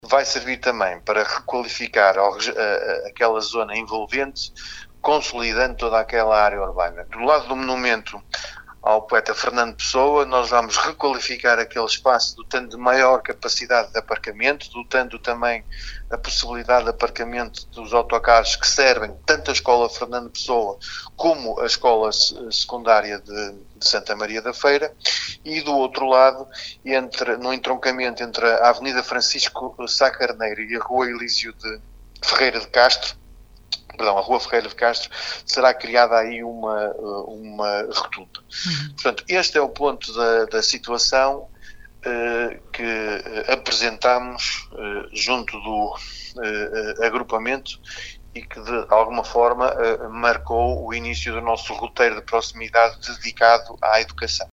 Questionado sobre o financiamento para a empreitada, Amadeu Albergaria afirmou que a Câmara irá aproveitar fundos comunitários destinados à Educação, se bem que uma fatia terá de vir também do Orçamento Municipal.